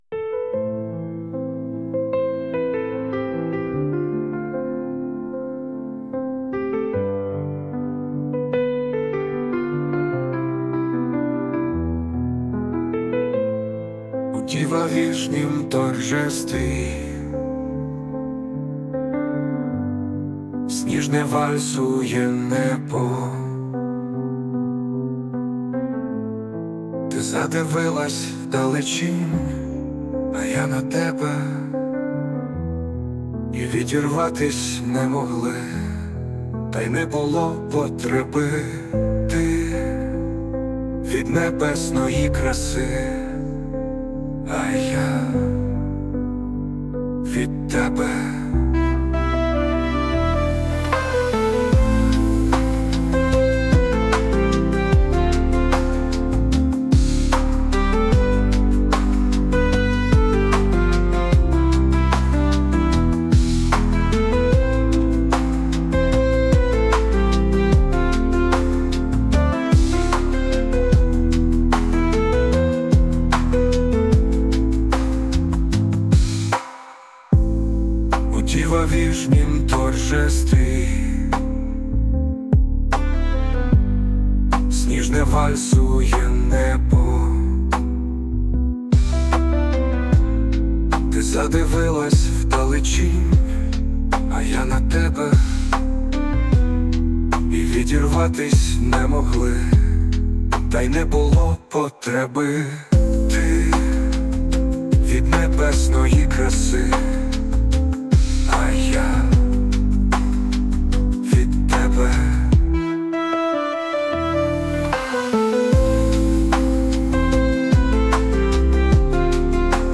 Музична композиція ШІ
ТИП: Пісня
СТИЛЬОВІ ЖАНРИ: Ліричний
Чудова танцювальна мелодія і прекрасні слова про